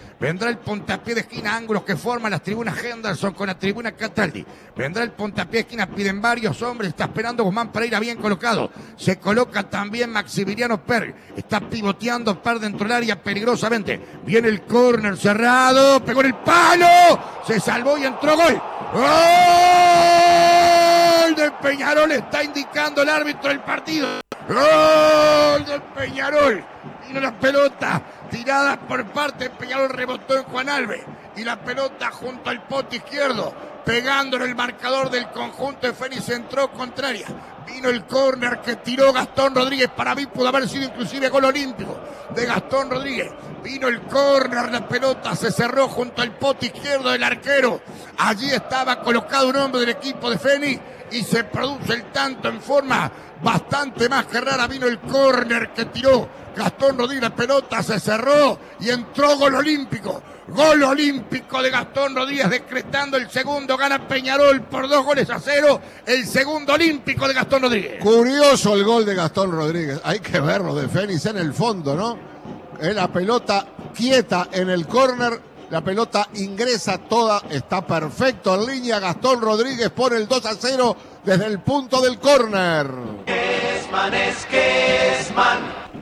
El primer tanto del carbonero lo marcó Hernan Petrik de cabeza y así lo relató Kesman: